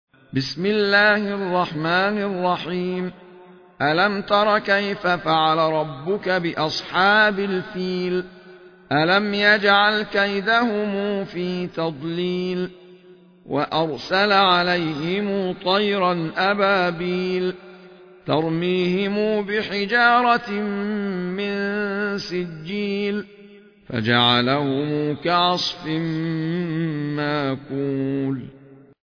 المصحف المرتل - ابن جماز عن أبي جعفر